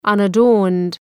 Προφορά
{,ʌnə’dɔ:rnd}